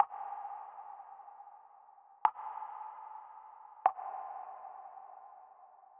Spooky Water Drops.wav